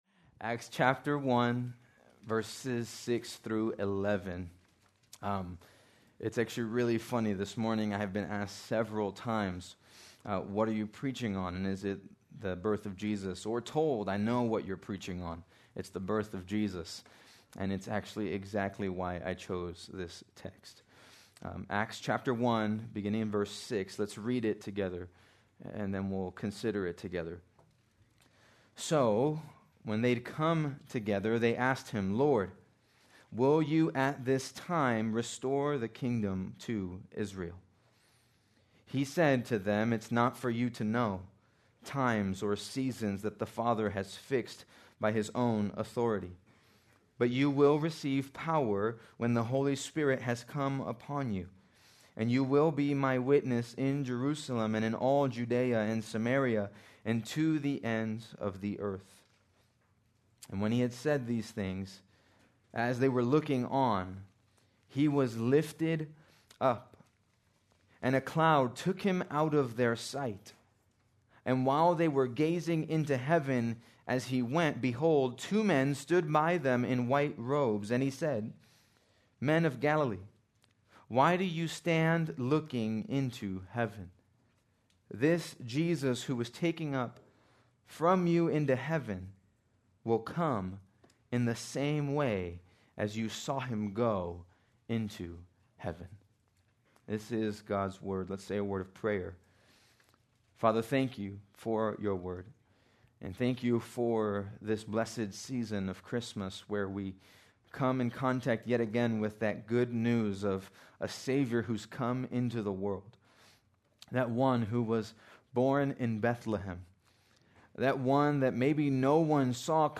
December 21, 2025 - Sermon | 180 Ministry | Grace Community Church